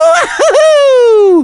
A voice clip from Mario in Super Mario Galaxy when he triple jumps.
SMG_Mario_Wahoo_(triple_jump).wav